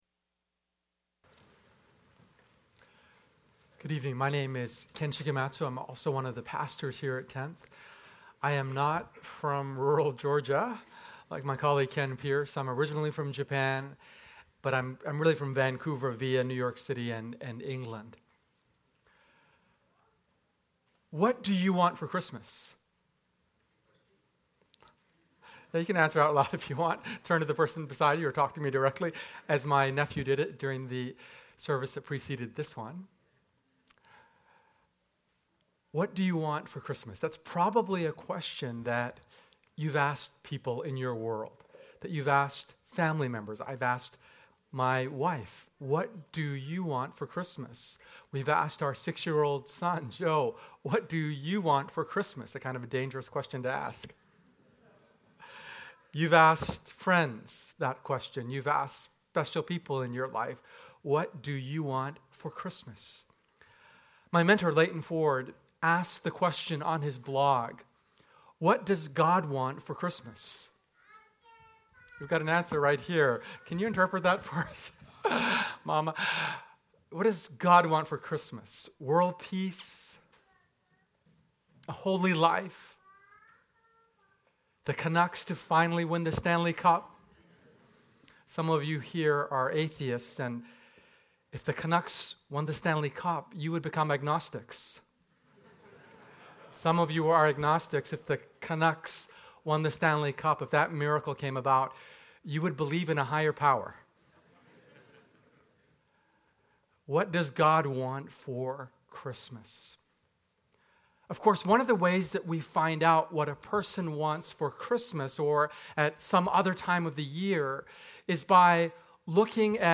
Christmas Eve message
Episode from Tenth Church Sermons